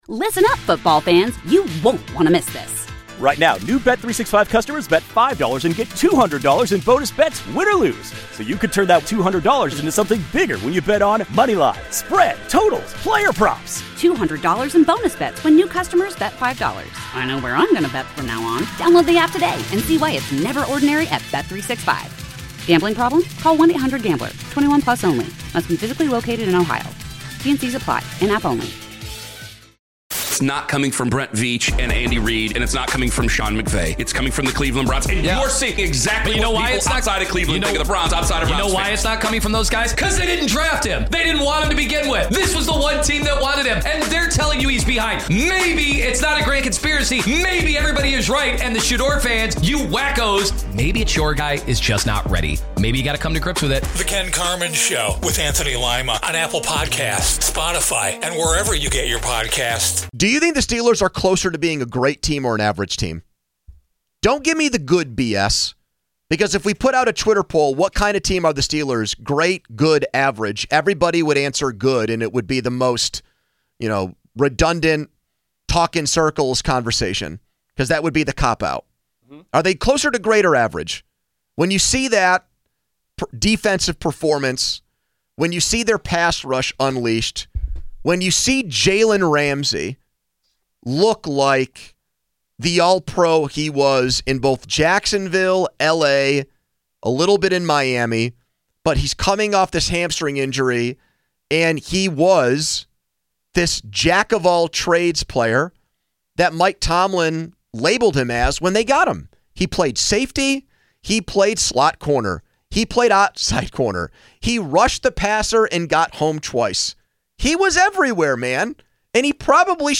A caller still believes the Steelers will still lose 8 games.